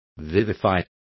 Complete with pronunciation of the translation of vivified.